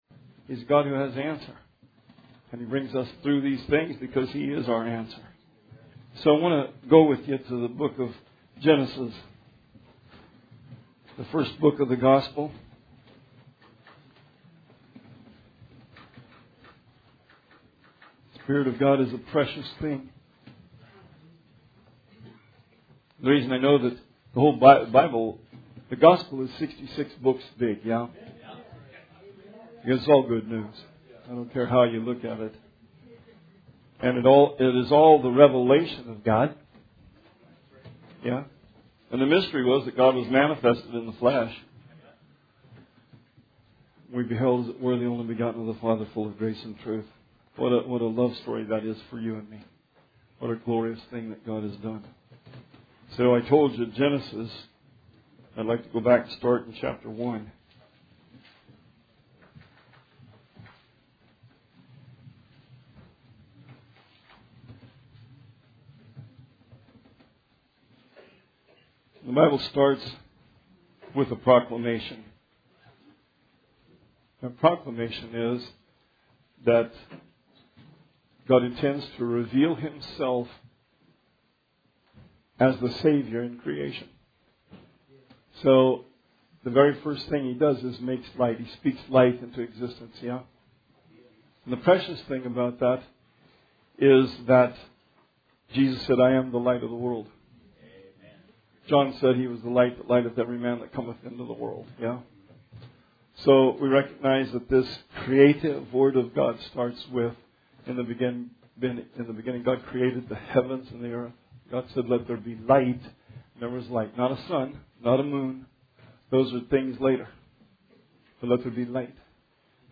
Sermon 12/22/19